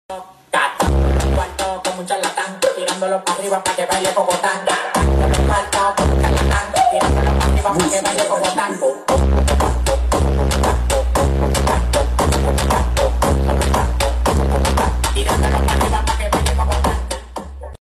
Gogo Dance Dembow